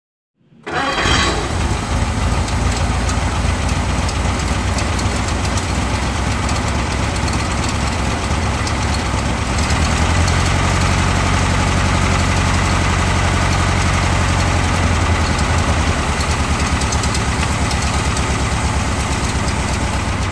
1948-Tatra-engine.mp3